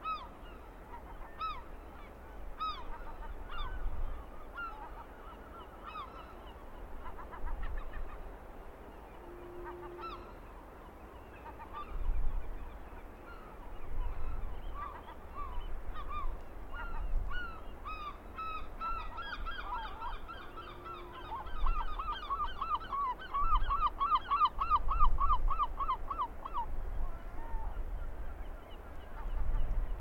Goéland argenté - Mes zoazos
goeland-argente.mp3